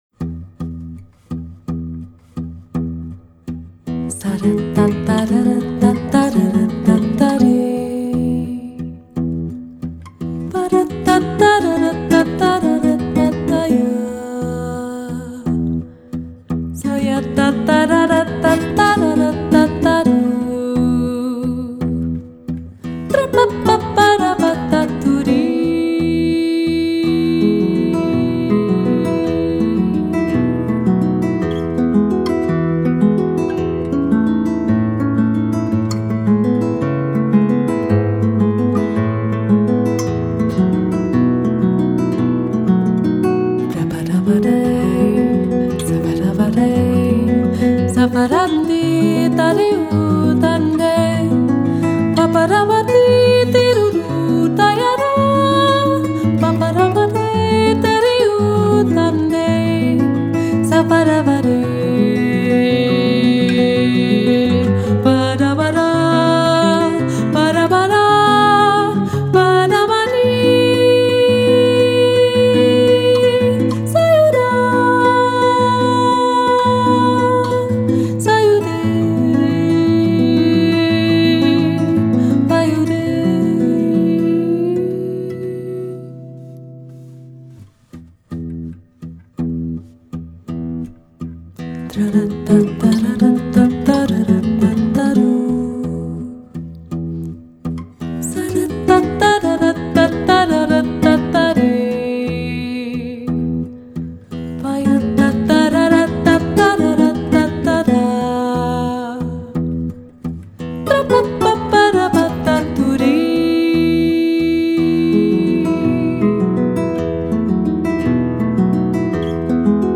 Argentijnse muziek van tango zangeres en gitarist
Andere folklore:
Tango duo zangeres gitaar Buenos Aires